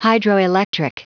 Prononciation du mot hydroelectric en anglais (fichier audio)
Prononciation du mot : hydroelectric
hydroelectric.wav